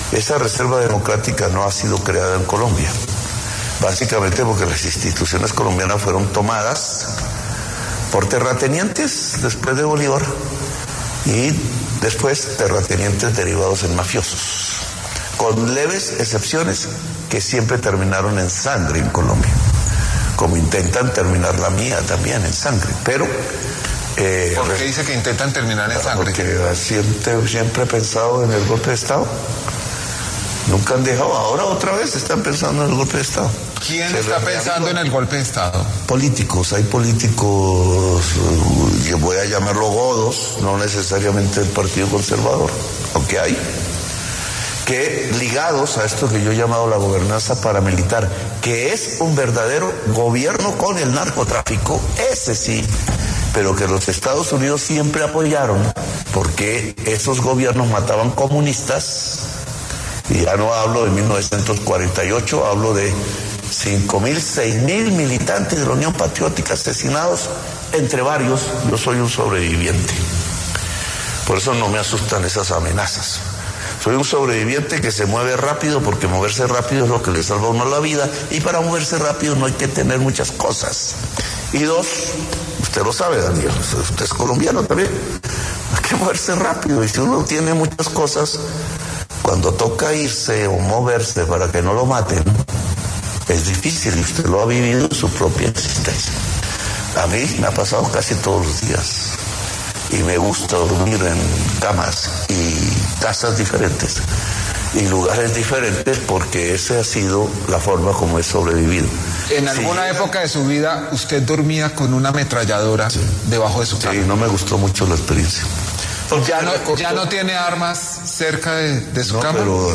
El presidente de la República, Gustavo Petro, habló con Daniel Coronell sobre diferentes temas de su Gobierno y volvió a referirse a un supuesto golpe de Estado que se estaría planeando en su contra.